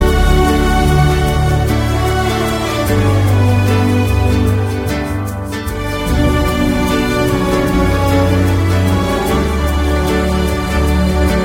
Kategori Klassisk